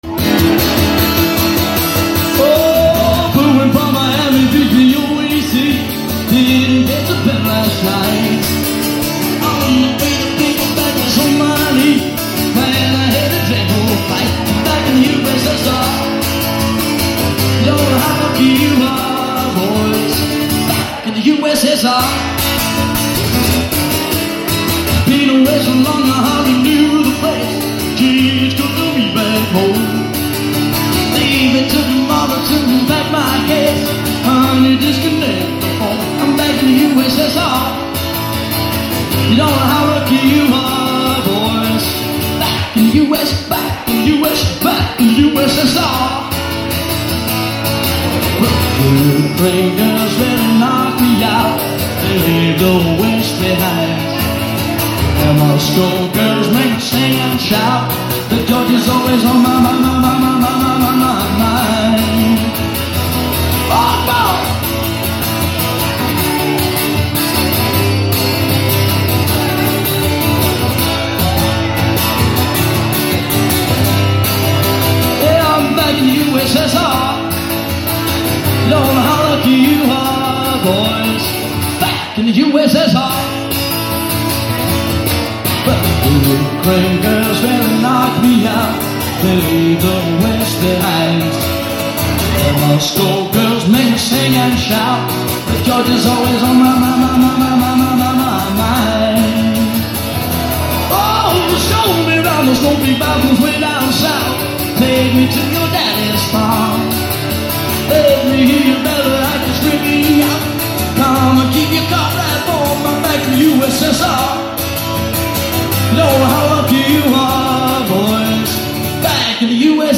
LIVE ACOUSTIC GUITAR COVER
AT THE CAVERN PUB IN LIVERPOOL